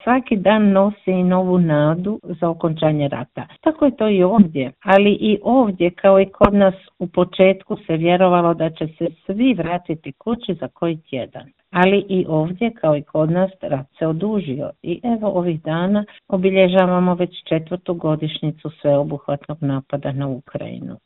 telefonskom Intervjuu Media servisa
hrvatsku veleposlanicu iz Kijeva Anicu Djamić